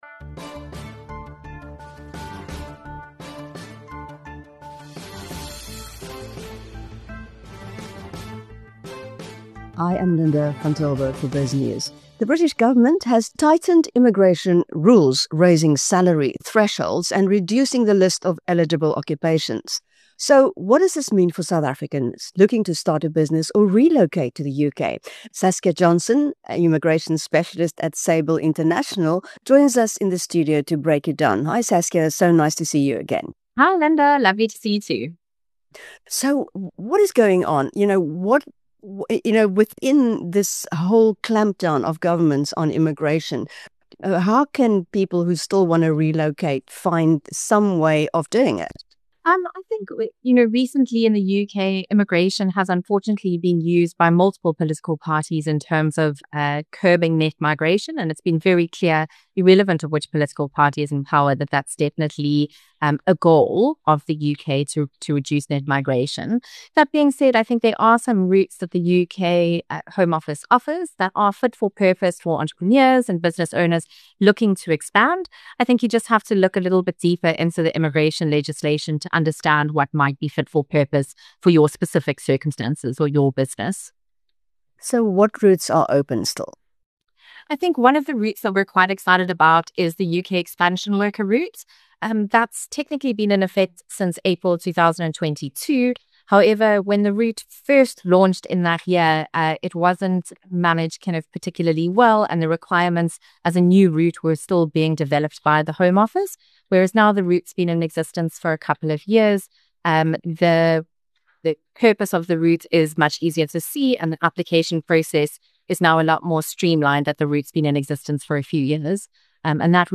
In an interview with BizNews